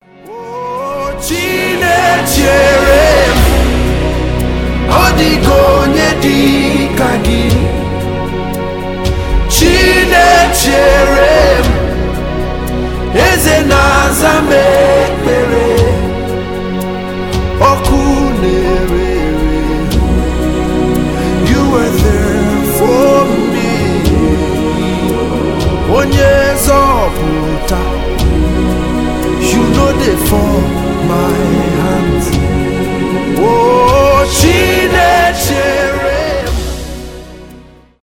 африканские , госпел , зарубежные , поп , христианские